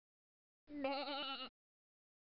Adobe After Effects: Проблемы рендеринга со звуковым эффектом ошибки